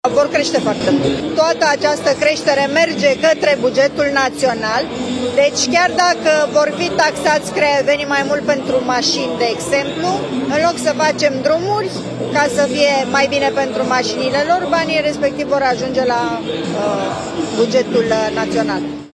Primarul orașului Craiova, Lia Olguța Vasilescu: „Toată această creștere merge către bugetul național”